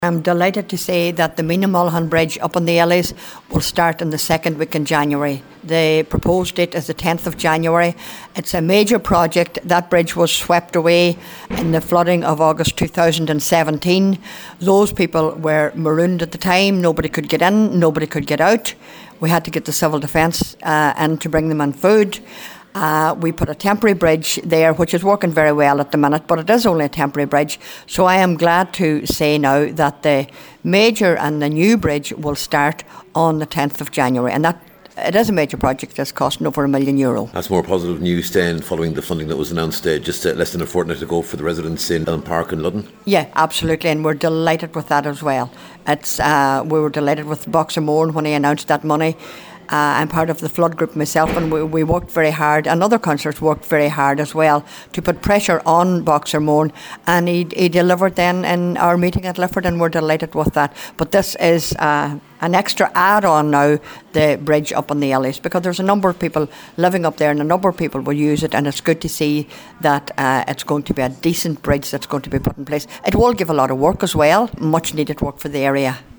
Cllr Rena Donaghy says it’s news the local community has been waiting for………..